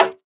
MagicCity Perc 1.wav